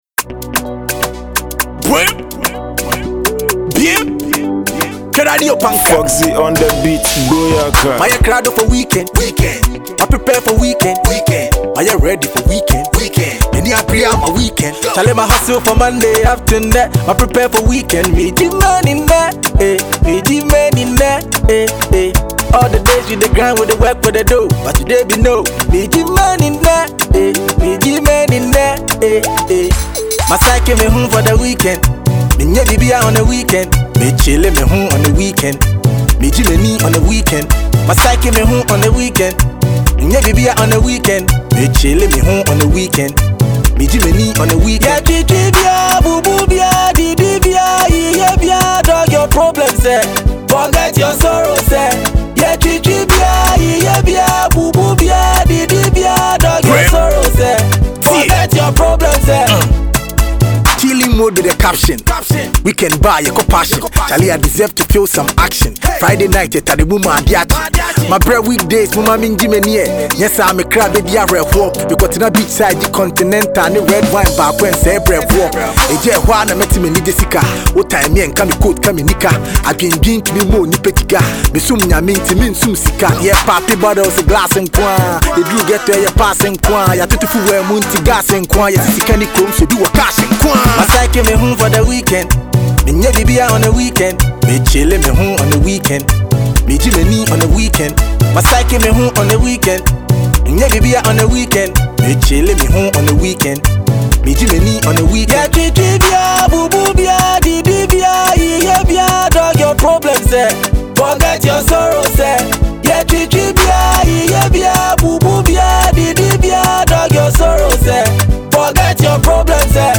Ghana Music Music
Ghanaian rapper